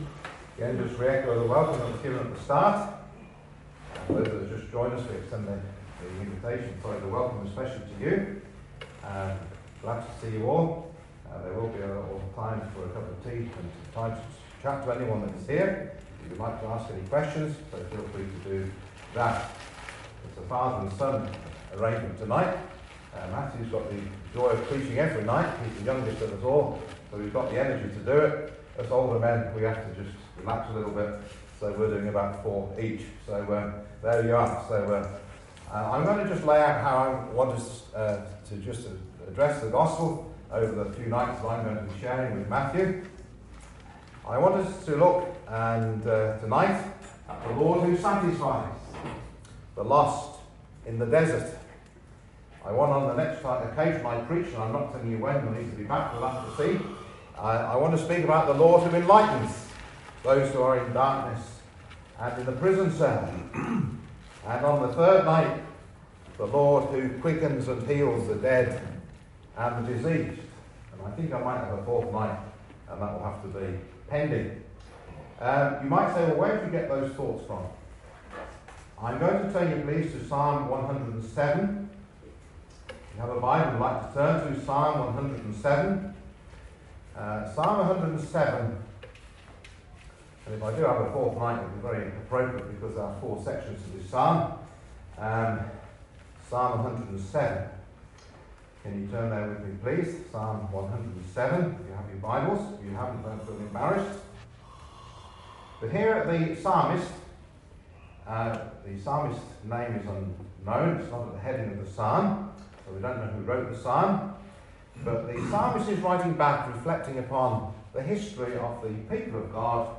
Service Type: Gospel